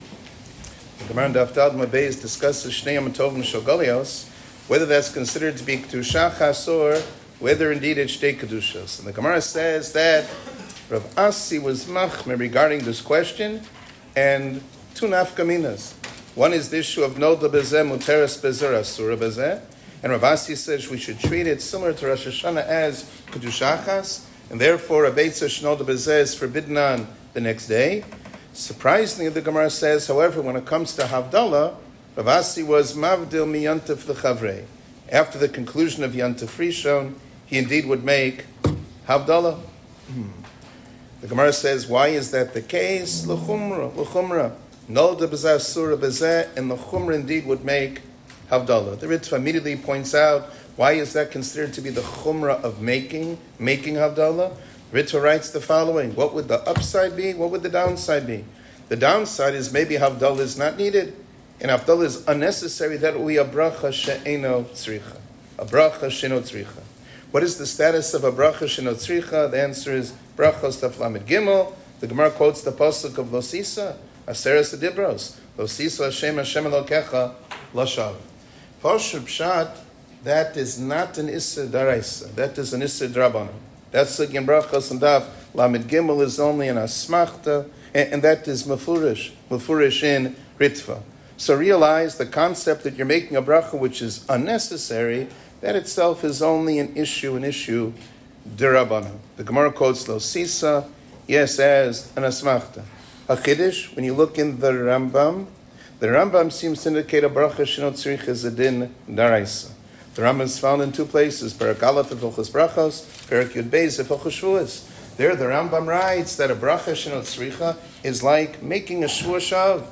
שיעור כללי - מצות הבדלה